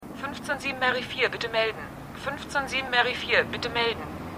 CHiPs_3x15_WblFunkstimme.mp3